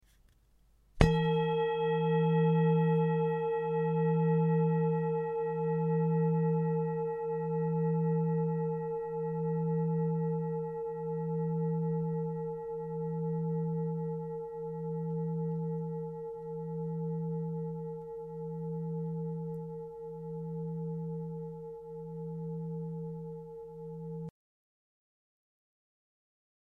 Tibetische  Klangschale HERZSCHALE 1191g KM67B
Durchmesser: 20,2 cm
Grundton: 161,74 Hz
1. Oberton: 457,55 Hz